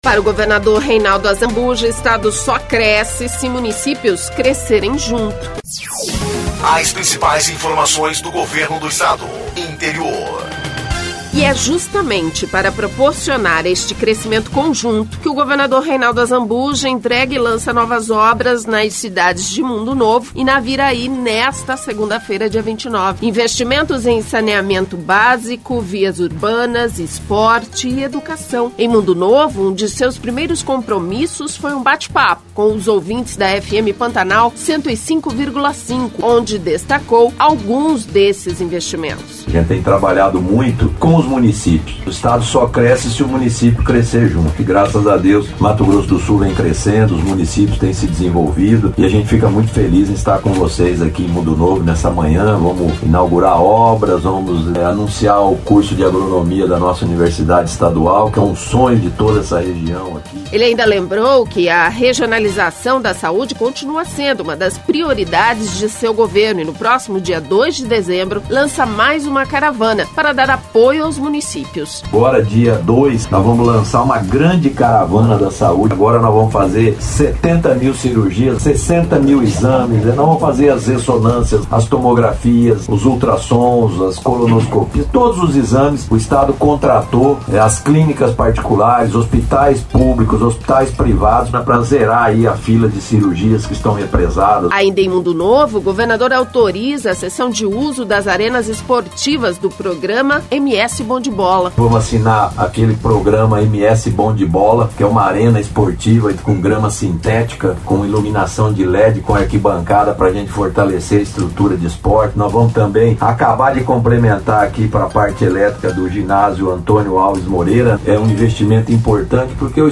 Em Mundo Novo, um de seus primeiros compromissos, foi um bate papo com os ouvintes da FM Pantanal 105,5, onde destacou alguns desses investimentos.